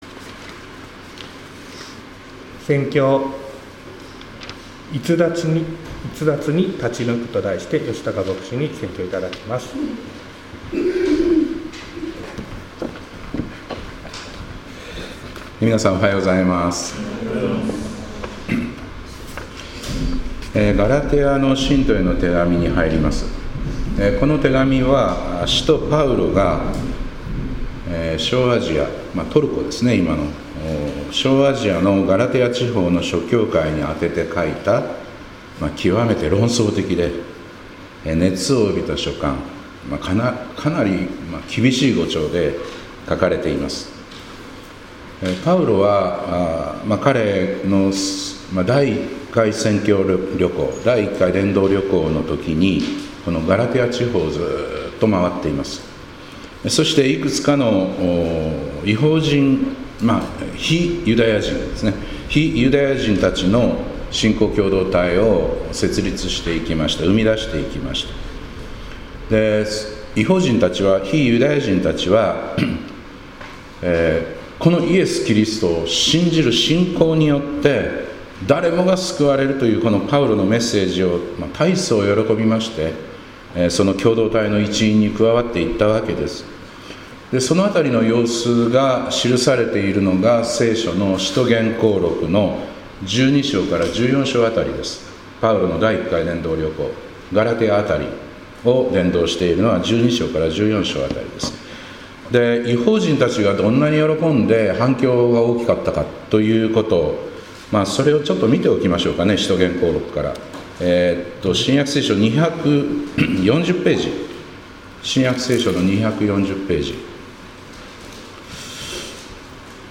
2025年5月4日礼拝「『逸脱』に立ち抜く」 - 市川八幡キリスト教会